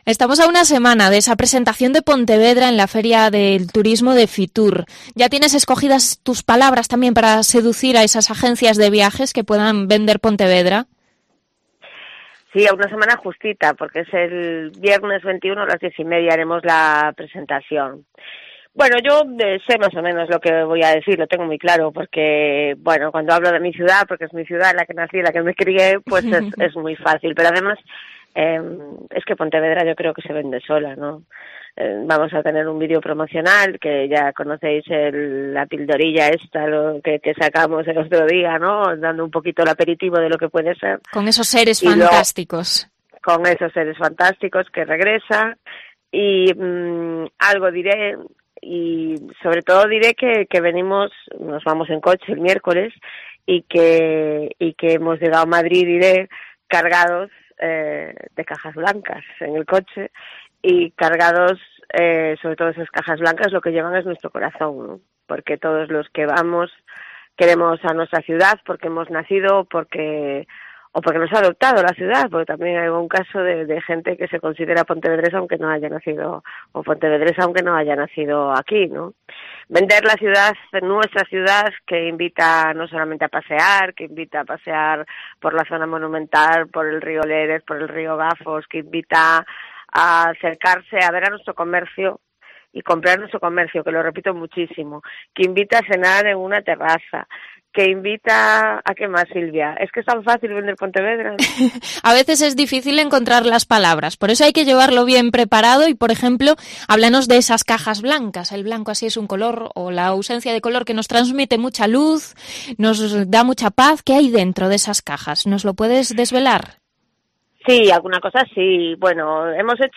Entrevista a la concejala de Turismo de Pontevedra, Yoya Blanco, a una semana de FITUR